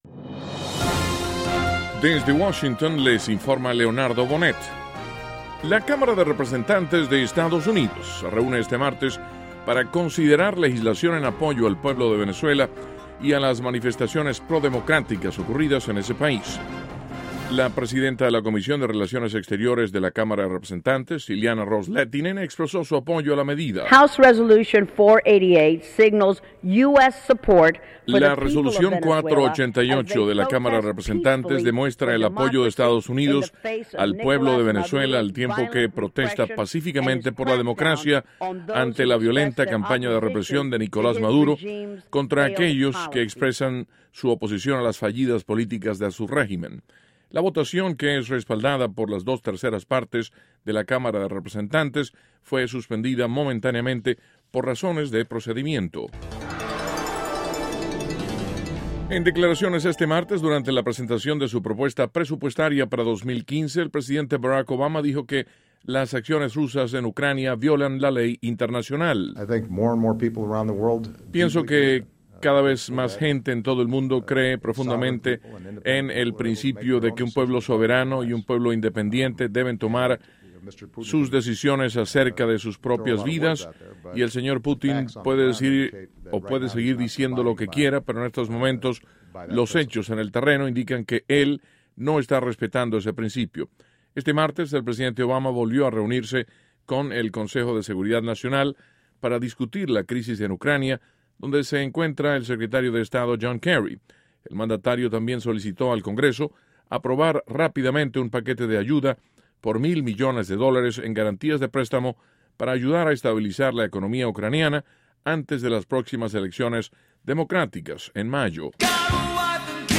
Duración: 1.- La legisladora cubano-estadounidense Ileana Ross-Lethinen, promueve resolución en apoyo al pueblo de Venezuela y a las manifestaciones prodemocráticas que ocurren en el país. 2.- Presidente Obama critica nuevamente al primer mandatario de Rusia, Vladimir Putin, por las acciones en Ucrania. (Sonido Obama) 3.- Bruce Springsteen será el encargado de concluir, en abril. un festival de música con motivo del Campeonato Nacional Universitario de Baloncesto de la NCAA.